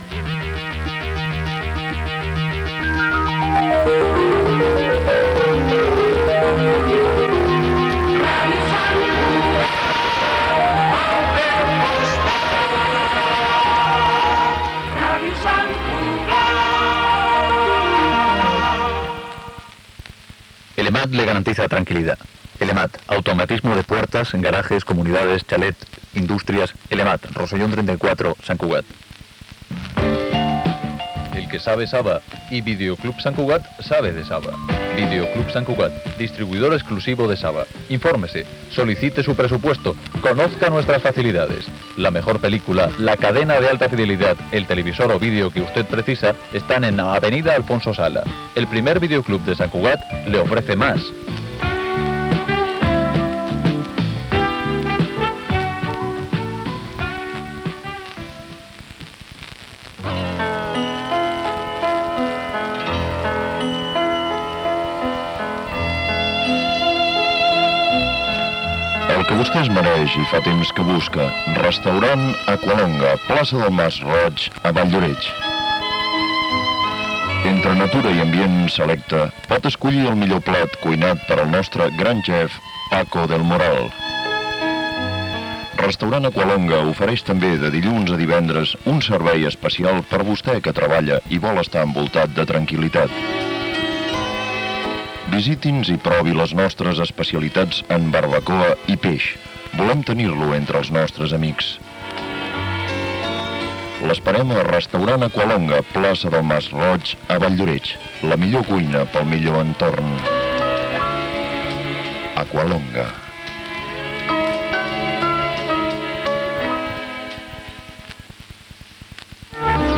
Indicatiu i publicitat
FM